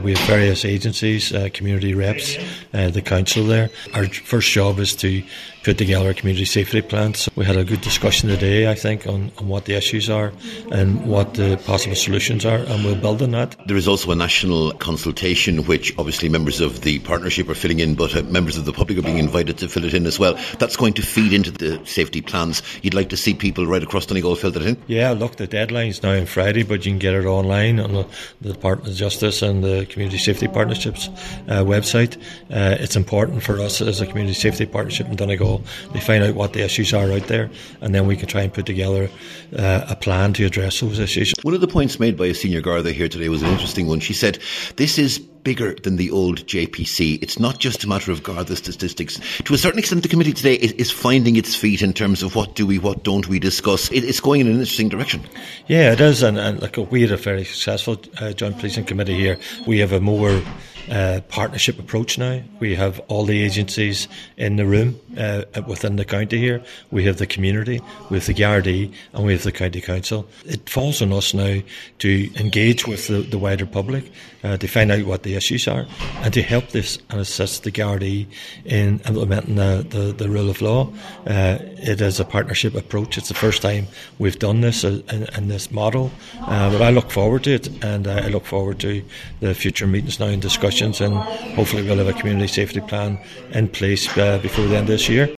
Speaking to Highland Radio News after the meeting, Chairperson Cllr Gerry McMonagle urged people to participate in a national consultation that accompanies the work on formulating the local plan.